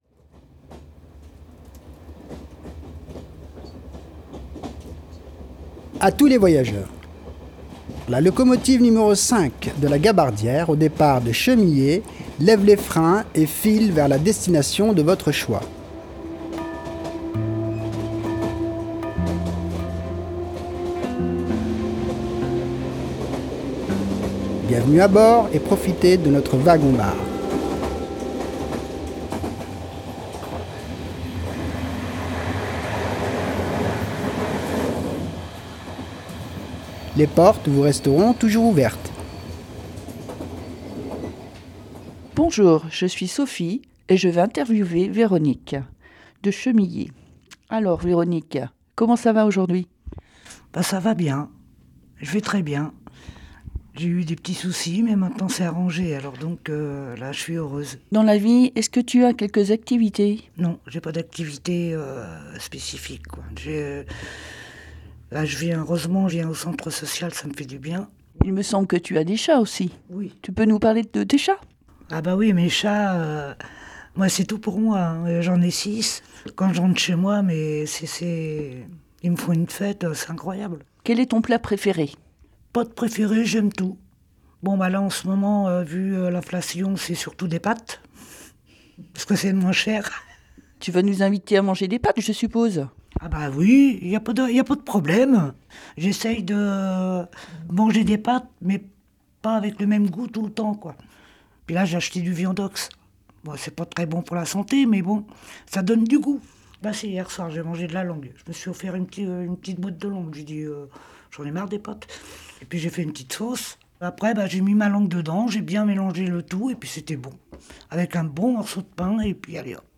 Un chouette documentaire sur la Locomotive